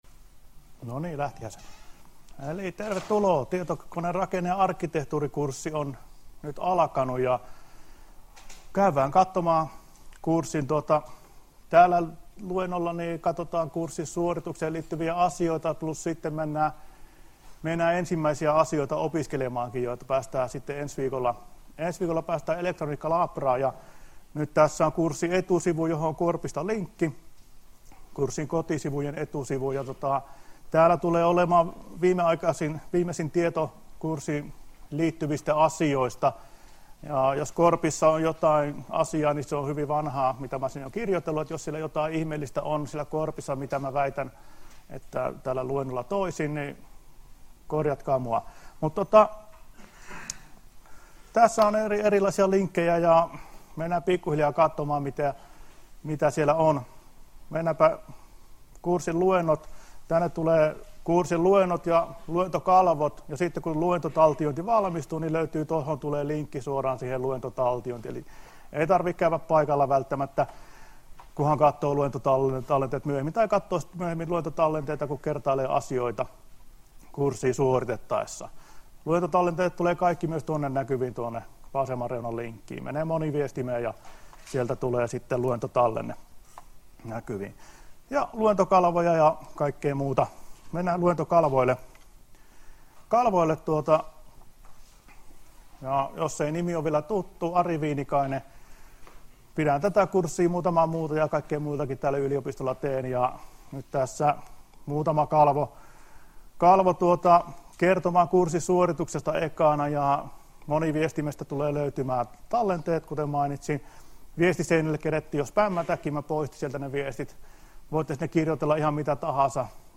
Luento 7.9. — Moniviestin